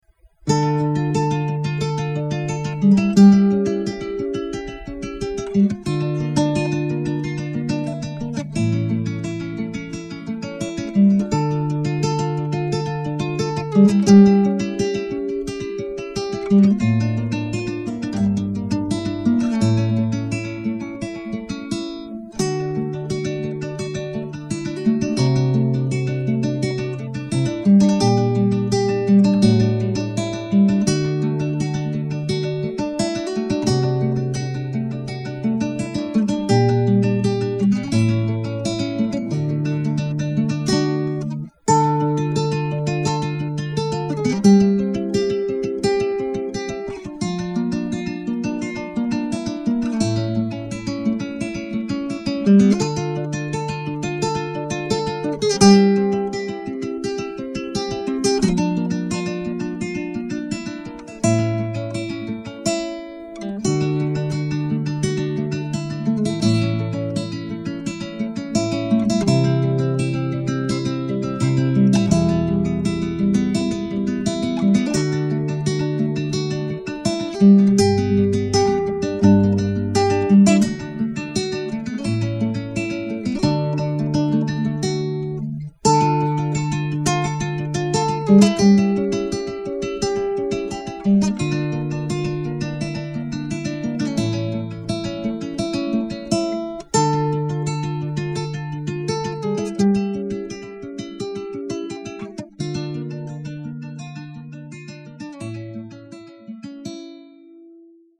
ре-минор